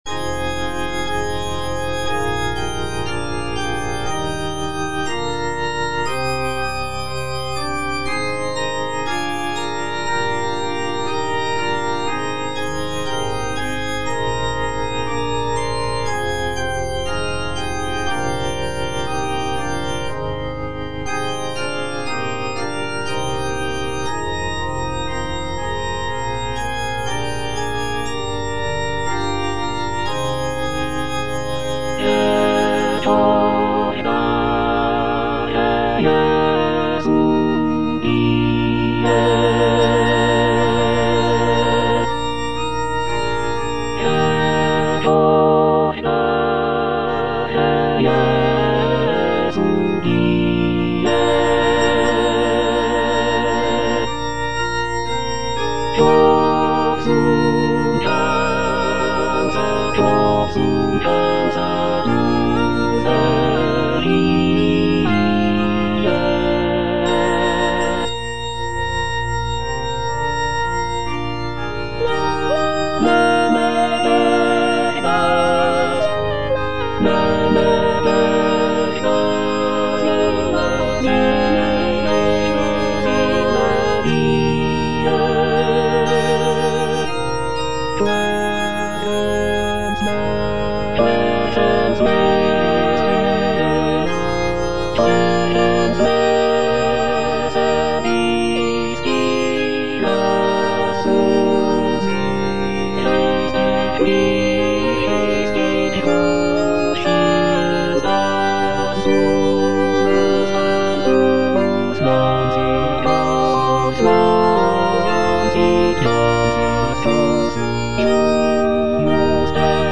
is a sacred choral work rooted in his Christian faith.
(All voices)